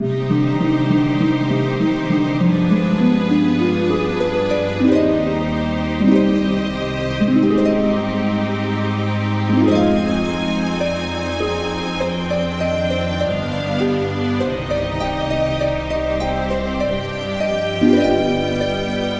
小弦乐和竖琴
描述：这是一首c小调的管弦乐循环曲；乐器包括第一和第二小提琴、中提琴、大提琴、低音提琴和音乐会竖琴。
Tag: 100 bpm Orchestral Loops Harp Loops 3.23 MB wav Key : Unknown